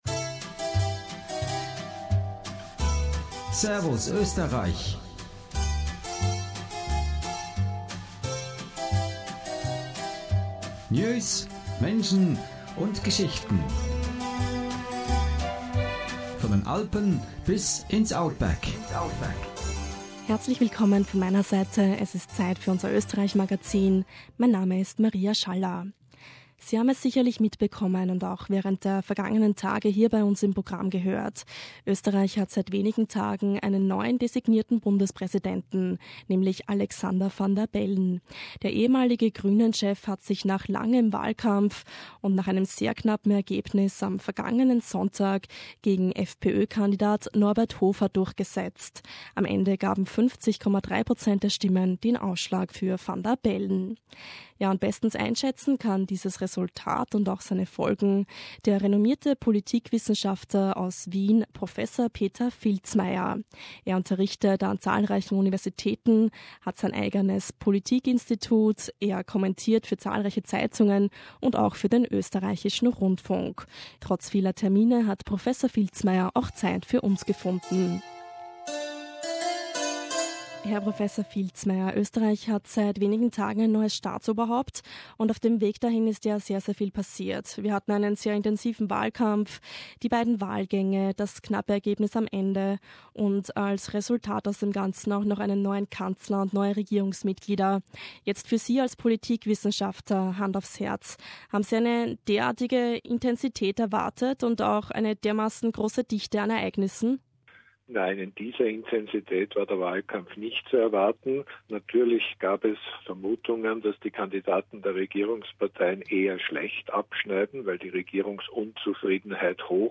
The Austrian population as well as the national and international media closely observed how the former leader of the Green Party Alexander Van der Bellen narrowly defeated far-right candidate Norbert Hofer to become Austria's next president. An interview with well-recognised political scientist Peter Filzmaier in Vienna.